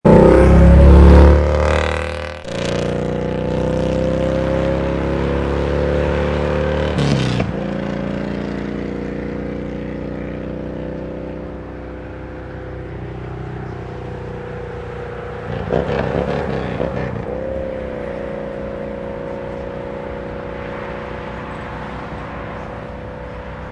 Motorcycle Passing